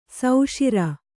♪ sauṣira